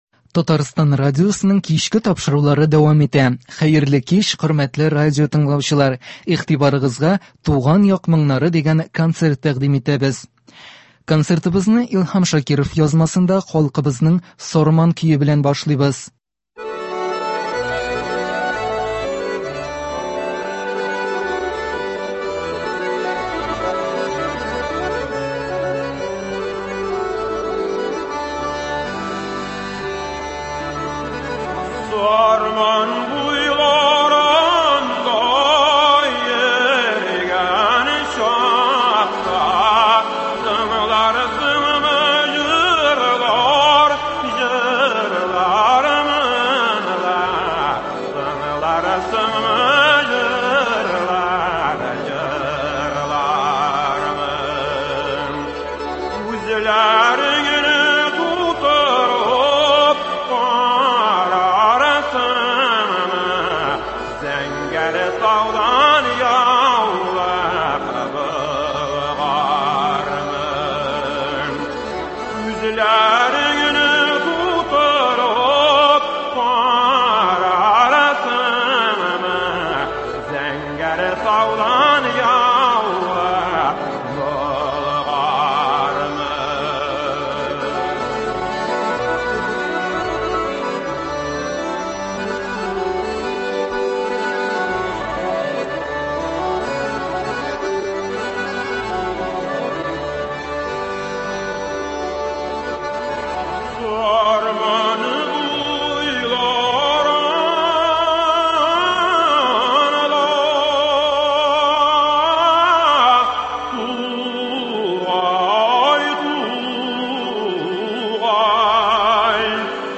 Көндезге концерт.